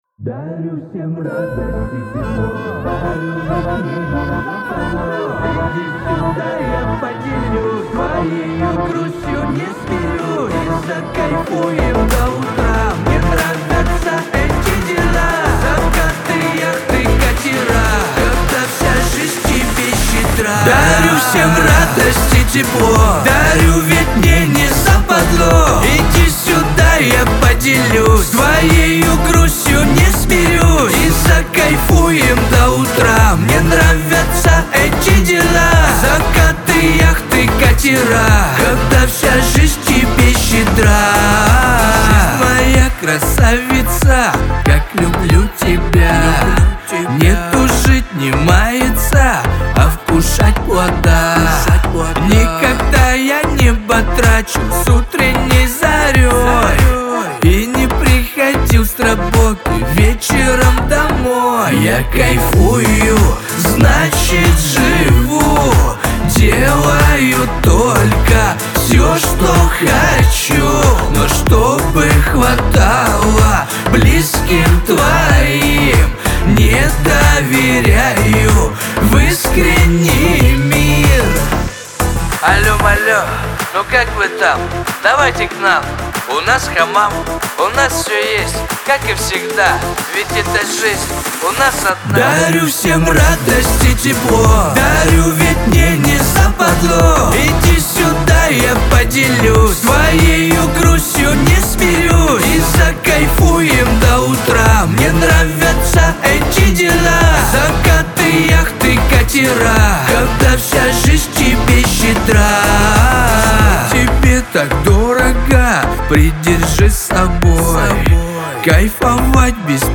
эстрада , pop , Лирика